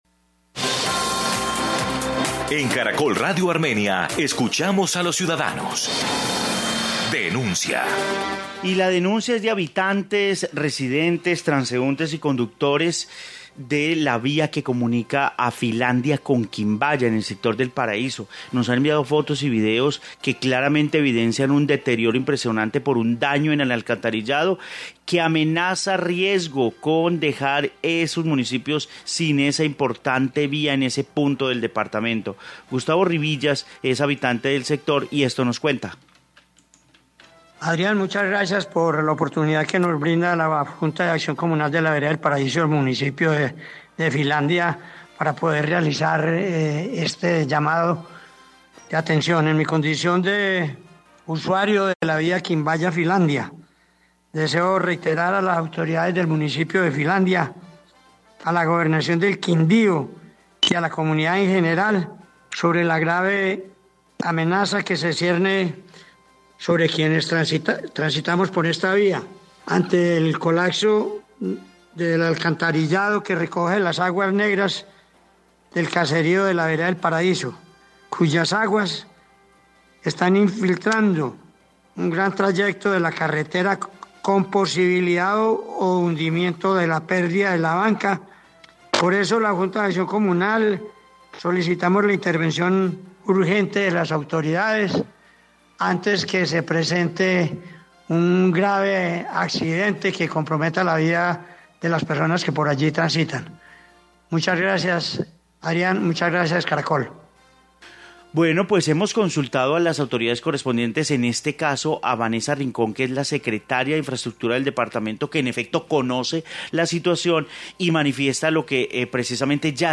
Informe vía Filandia - Quimbaya en Quindío